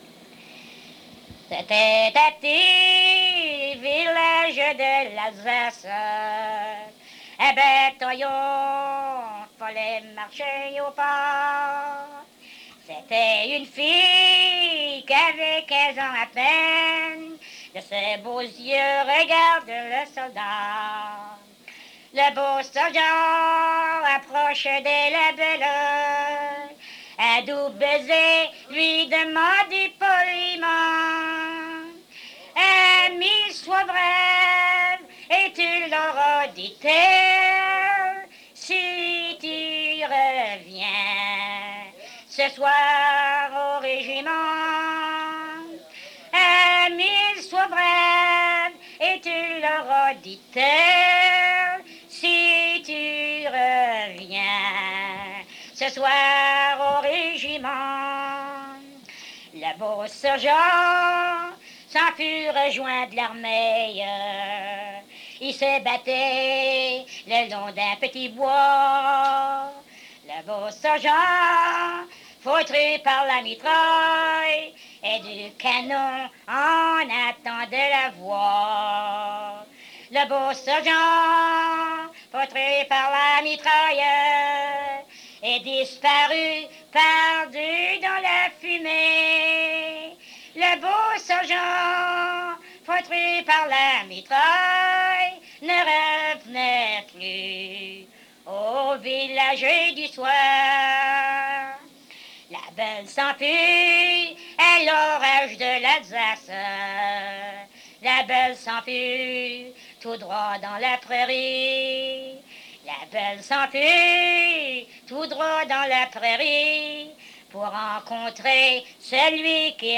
Chanson Item Type Metadata